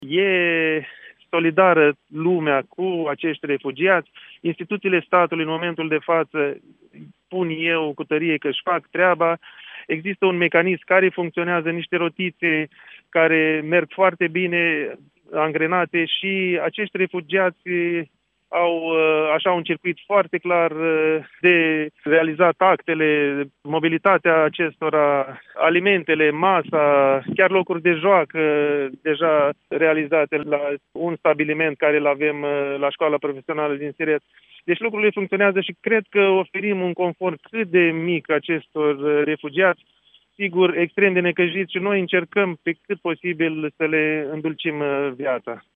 Primarul din Siret, județul Suceava, Adrian Popoiu a declarat că pe la acest punct de trecere a frontierei continuă să sosească refugiați, dar autoritățile s-au organizat foarte bine, iar cetățenii ucraineni sunt ajutați astfel încât formalitățile să fie îndeplinite: